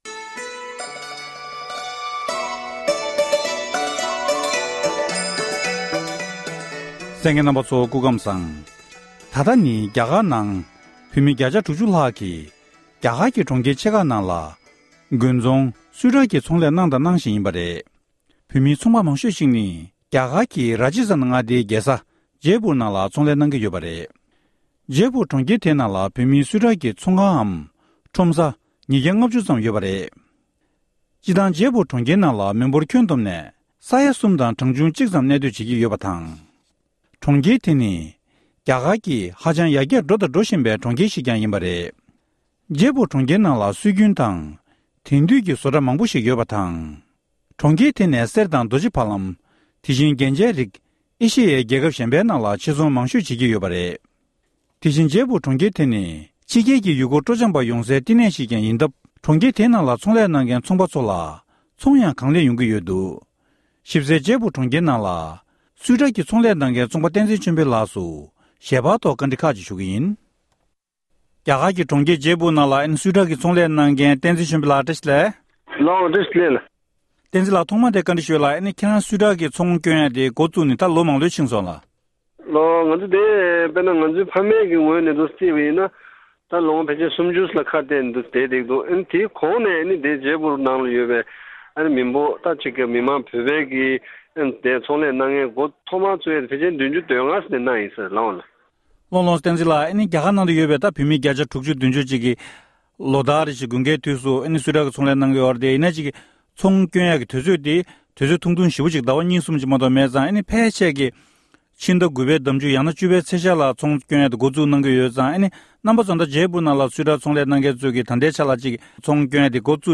གནས་འདྲི་